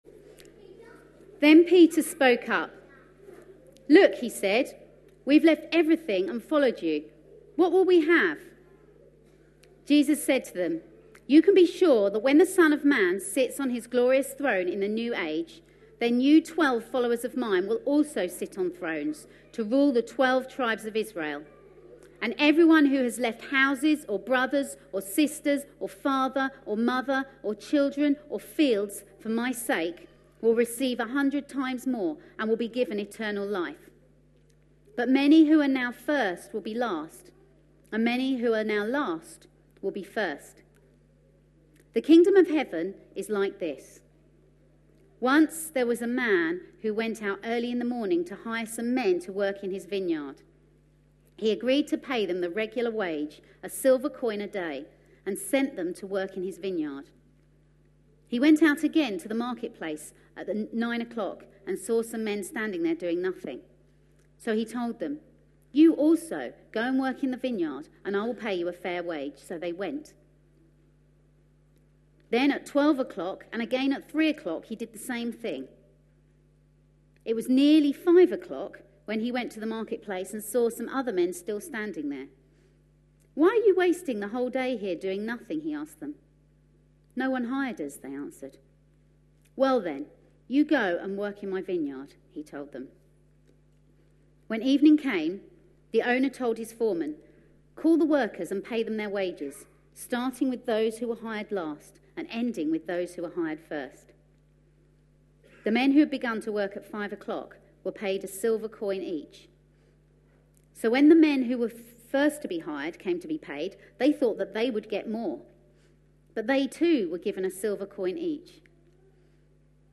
A sermon preached on 18th October, 2015.